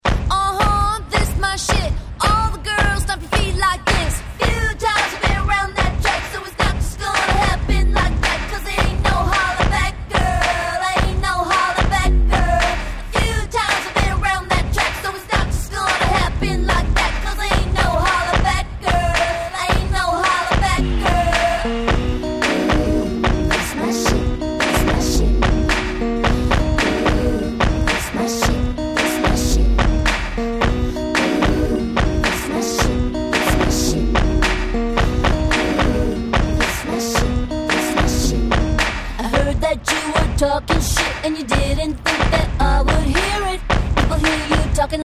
05' Big Hit R&B♩